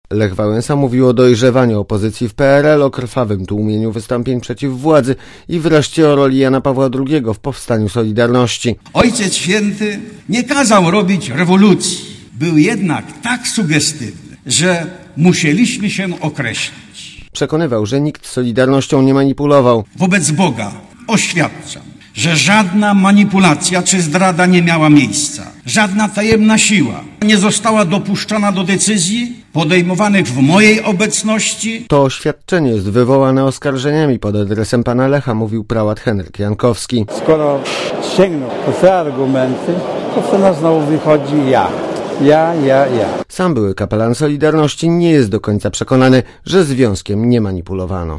solidarnosc_-_obchody_w_sejmie.mp3